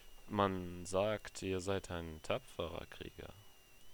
Deutsche Sprecher (m)